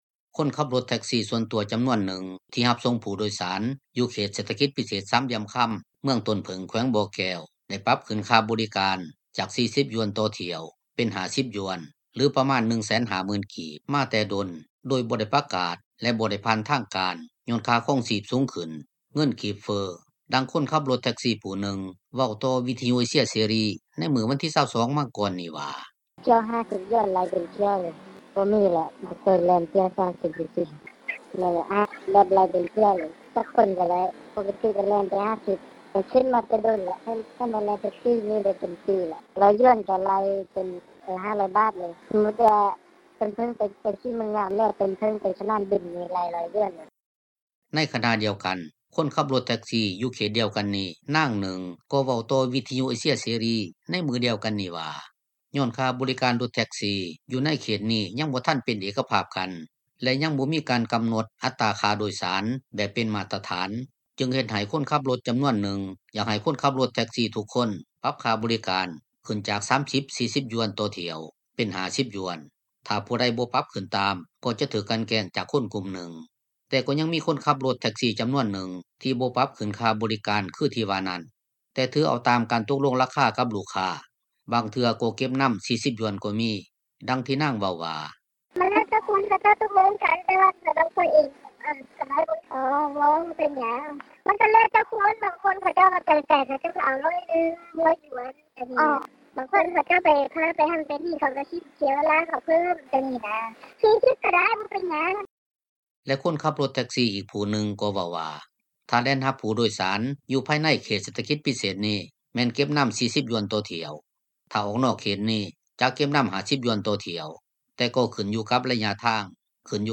ດັ່ງຄົນຂັບລົດແທັກຊີ້ ຜູ້ໜຶ່ງ ເວົ້າຕໍ່ວິທຍຸເອເຊັຽເສຣີ ໃນມື້ວັນທີ 22 ມັງກອນນີ້ວ່າ: